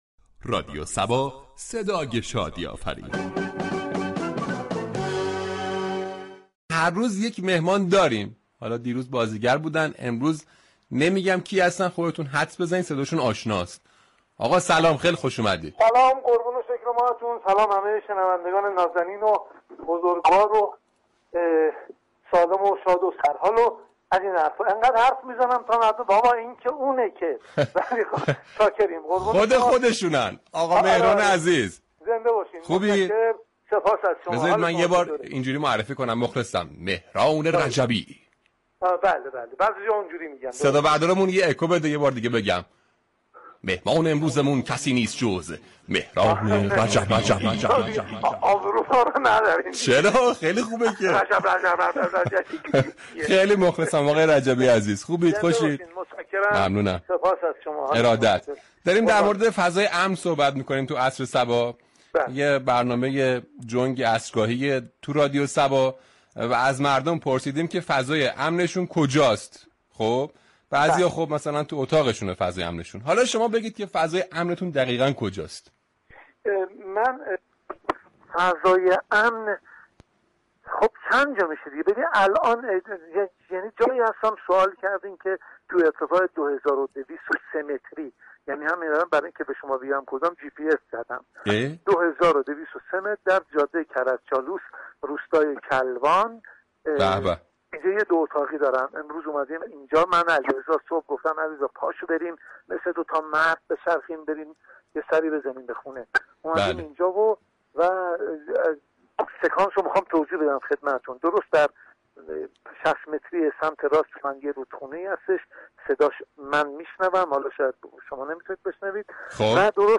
گفتگوی "عصر صبا" با مهران رجبی